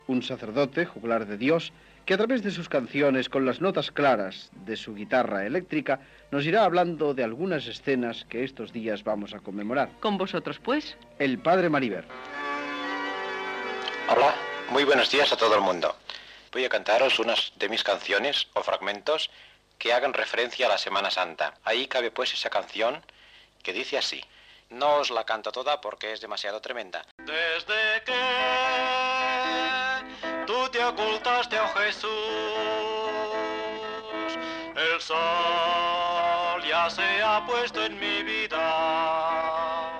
Religió